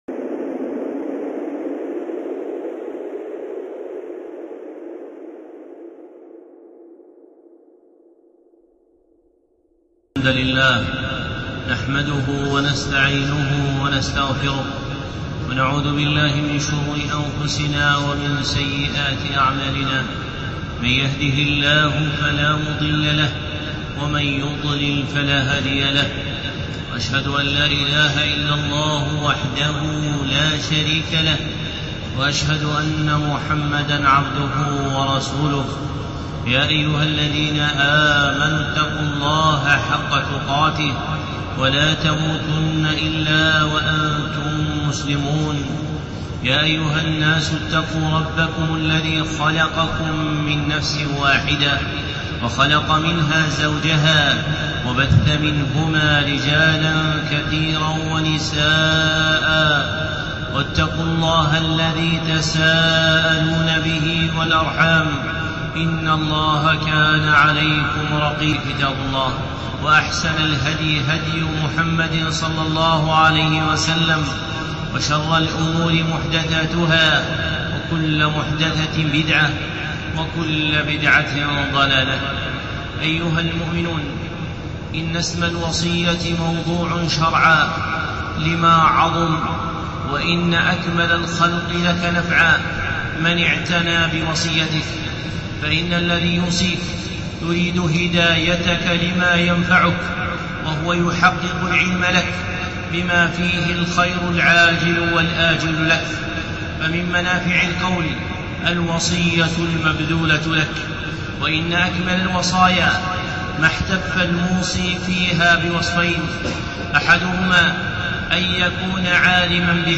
خطبة (أم الوصايا الإلهية)